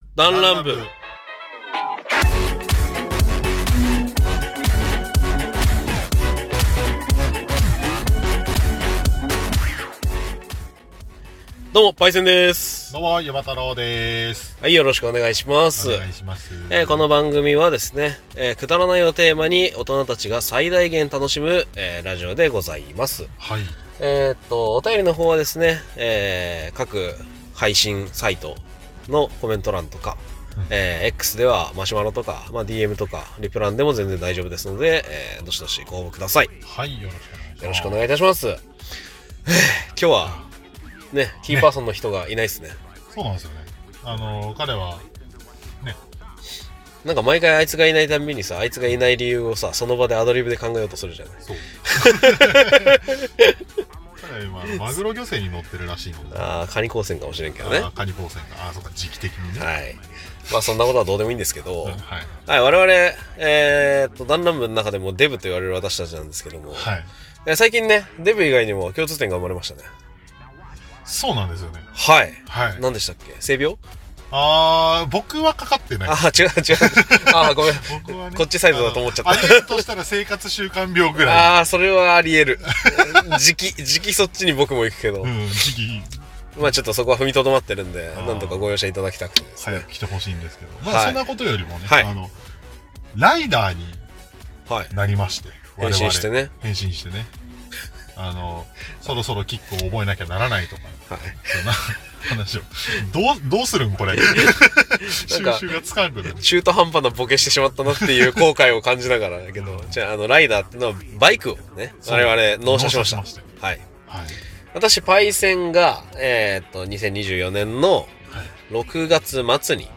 だんらん部 -アラサー男達の勝手なる雑談会-